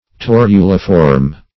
Search Result for " torulaform" : The Collaborative International Dictionary of English v.0.48: Torulaform \Tor"u*la*form`\, a. (Biol.) Having the appearance of a torula; in the form of a little chain; as, a torulaform string of micrococci.